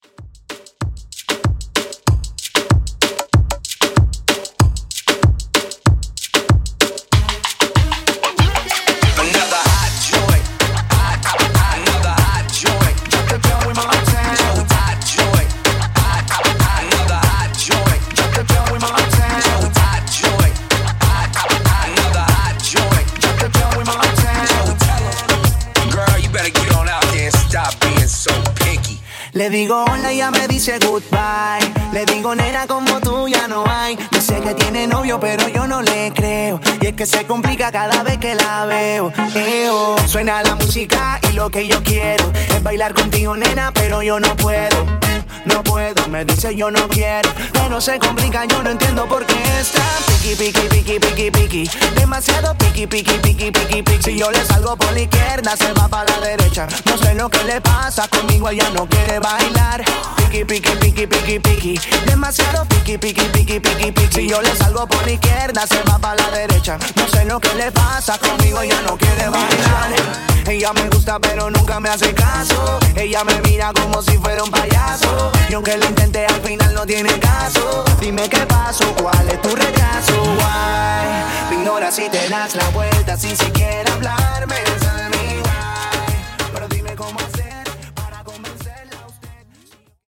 Urbano Moombah)Date Added